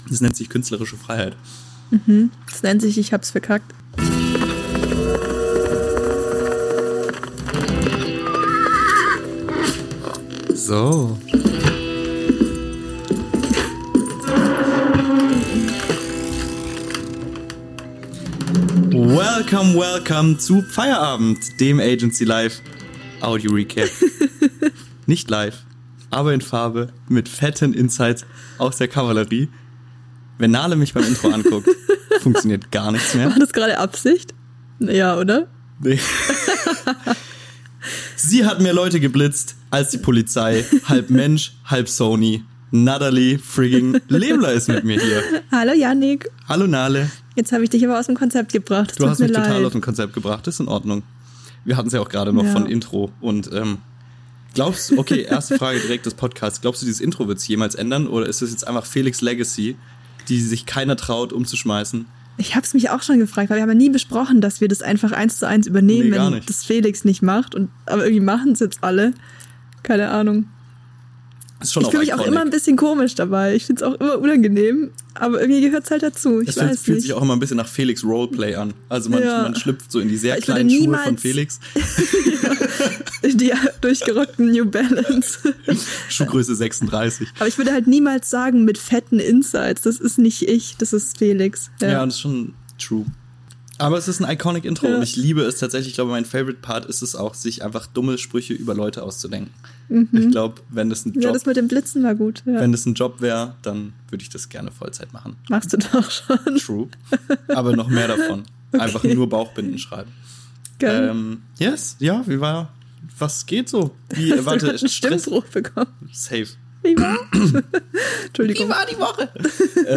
Das alte, junge Duo ist endlich wieder vereint – hier und da knackt es zwar, aber das bringt Agentur halt so mit sich. Was folgt ist eine ganz traditionelle Pfolge der alten Schule.